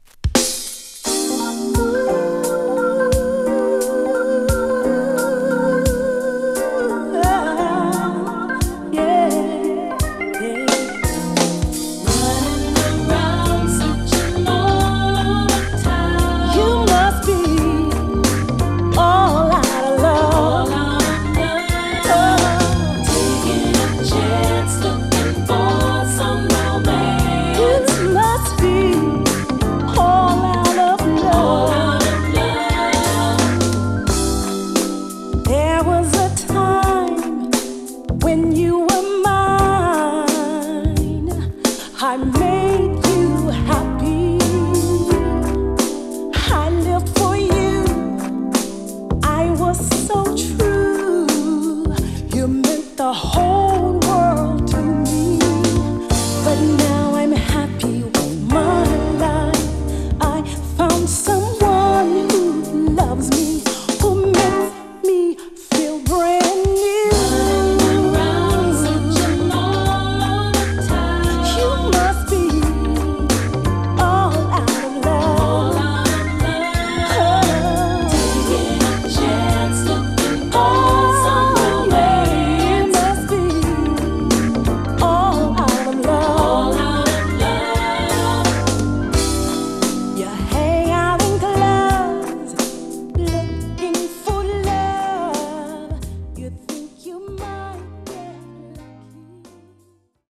2. > SOUL/FUNK
メロウ、フリーソウルな名曲を多数収録！